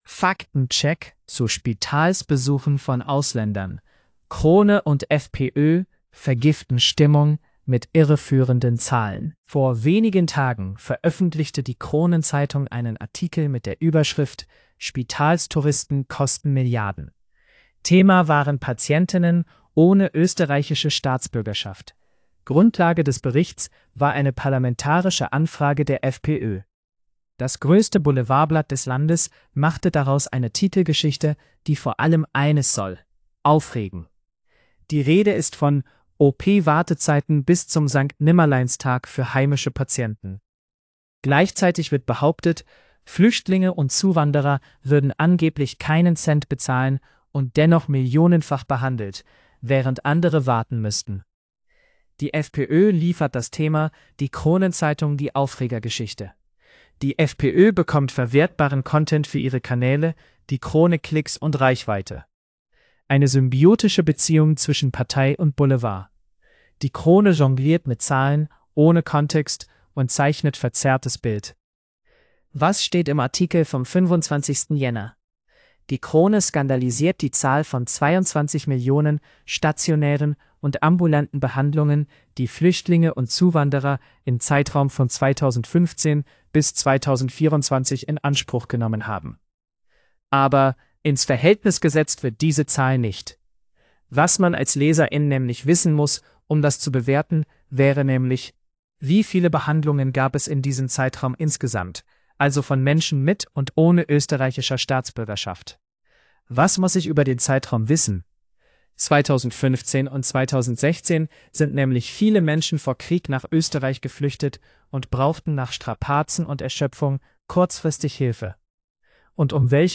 Itt meghallgathatod a cikk mesterséges intelligencia által generált hangos verzióját.